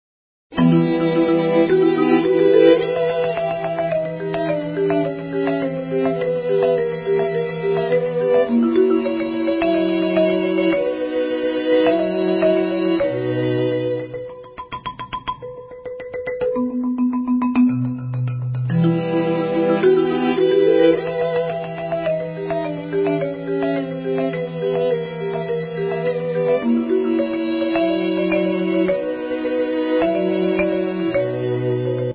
Classical guitar
Flute
Violins
Cello
Keyboards
Vocals
Marimba / Vibes
String-orchestra